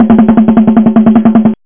RunningDrum.mp3